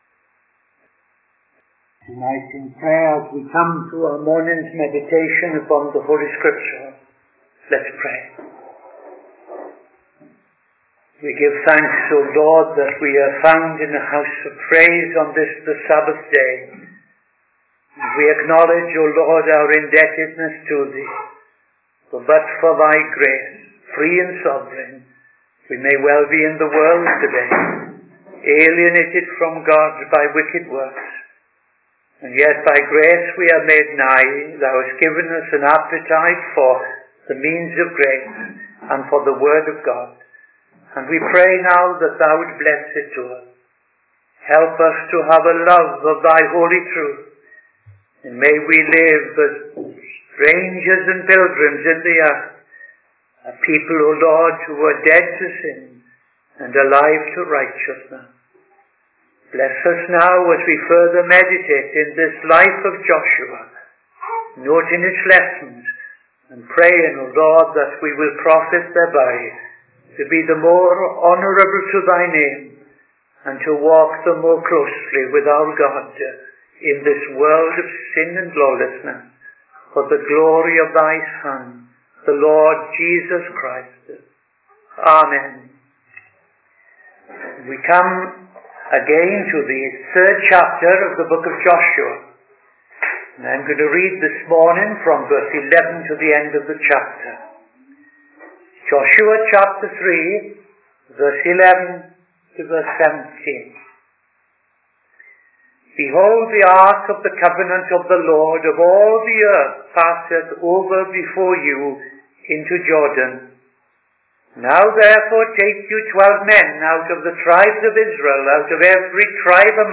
Midday Sermon 30th November 2025